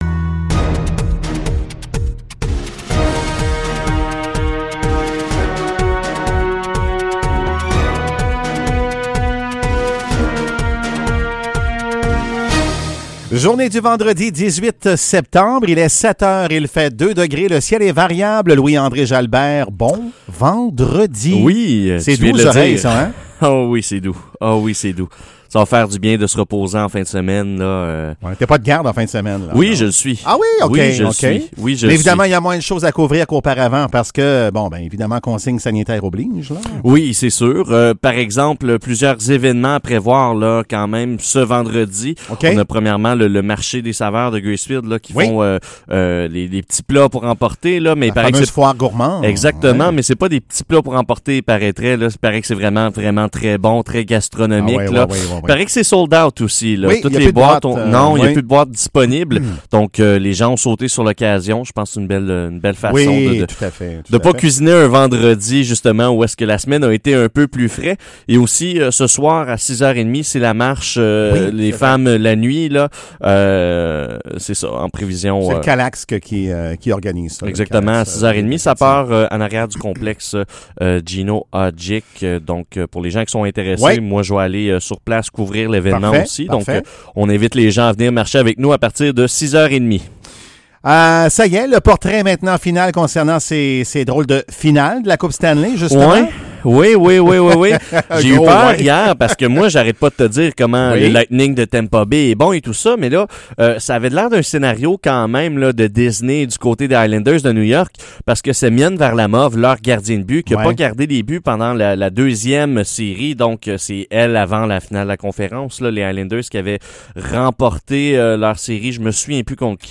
Nouvelles locales - 18 septembre 2020 - 7 h